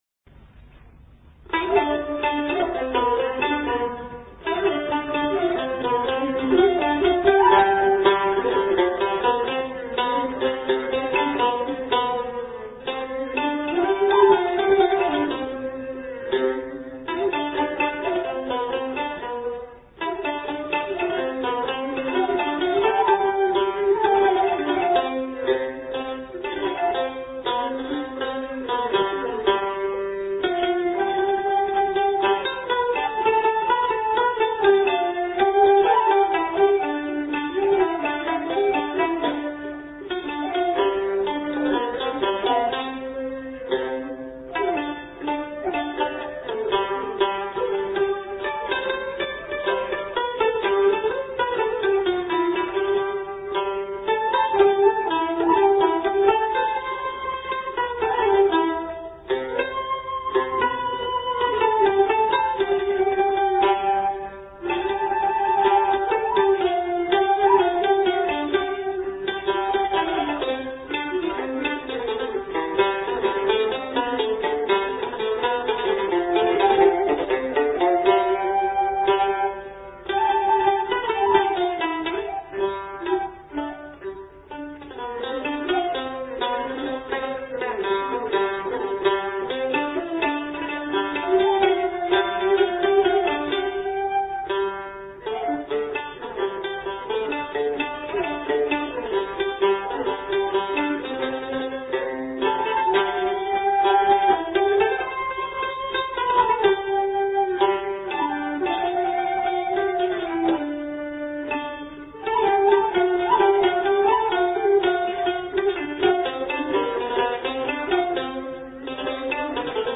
演奏：秦琴 椰胡 洞蕭
近來有人提倡復原古樂器，本曲是用秦琴，椰胡，和洞蕭三重奏。